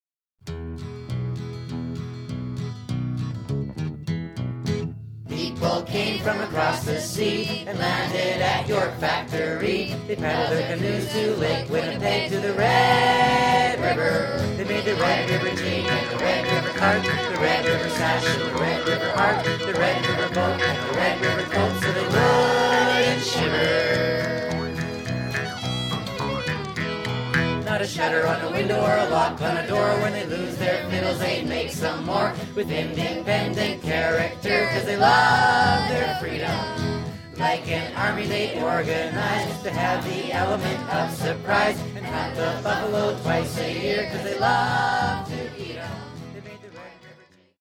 Lots of traditional Métis type fiddle music.